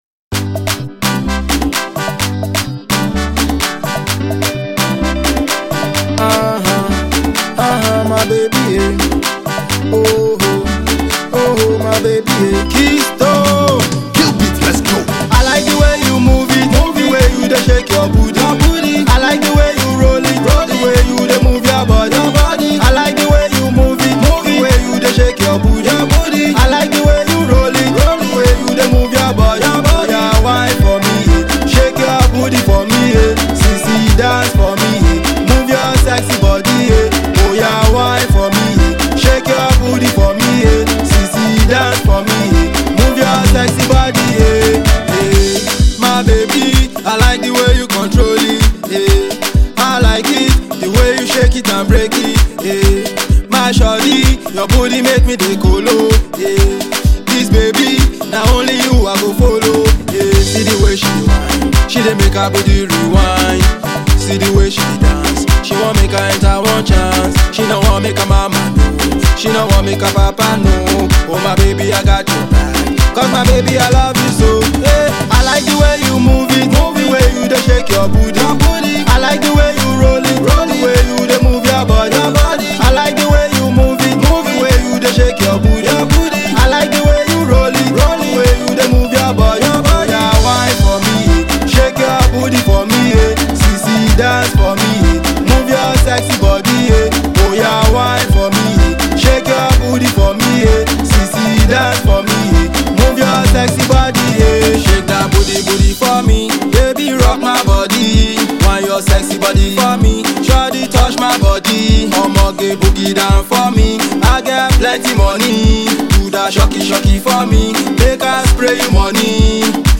High Tempo Banger